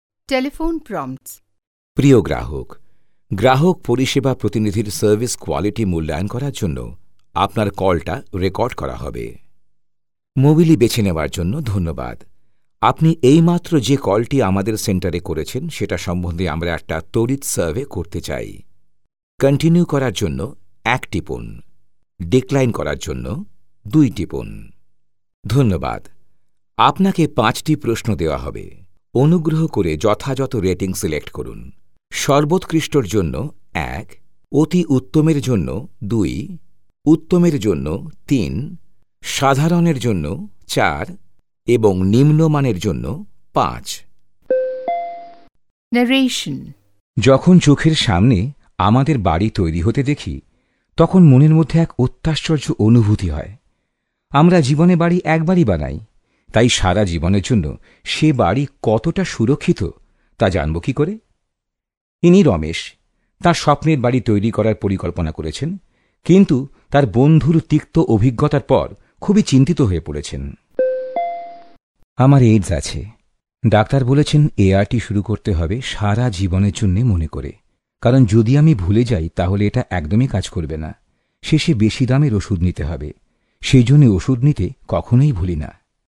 indian male voice over artist